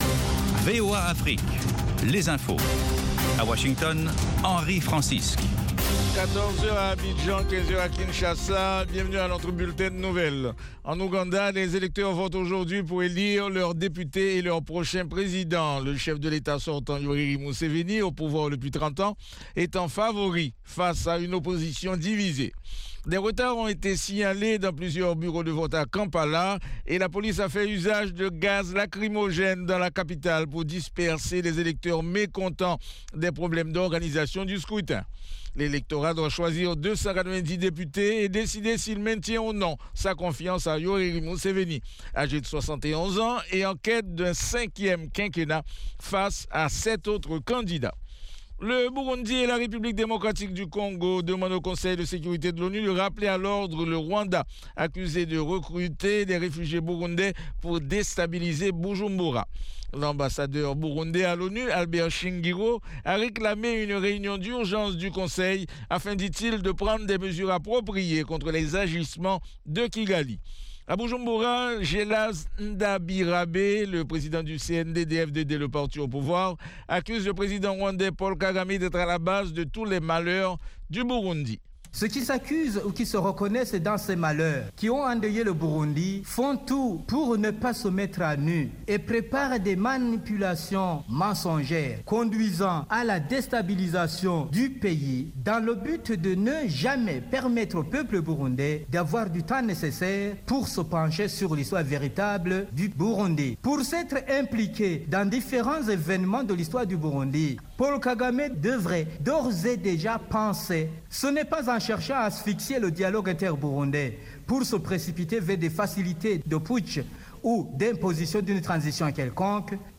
5 Minute Newscast